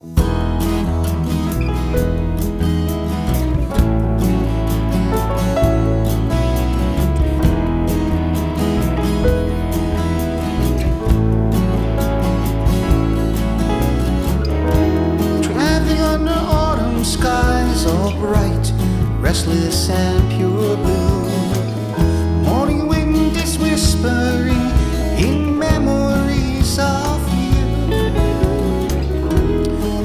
Lead Vocals and Rhythm Guitar
Bass, Keyboards, and Harmony Vocals